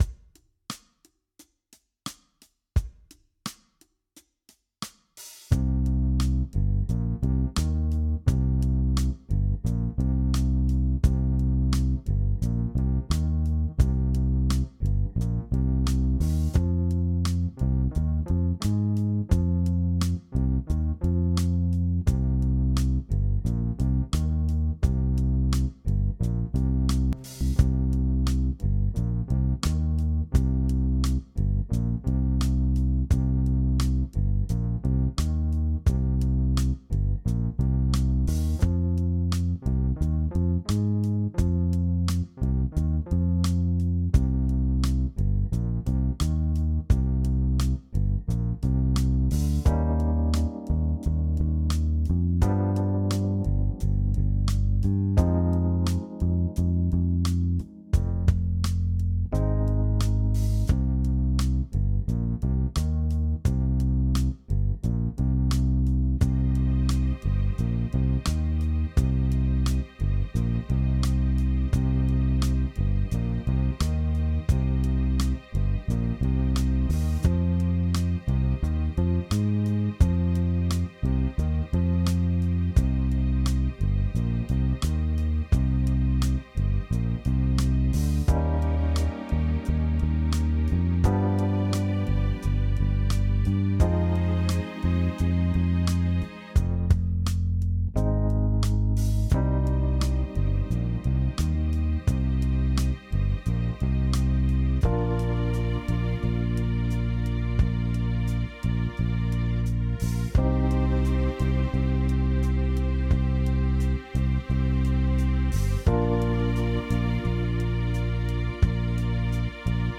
Jam Track
Jam track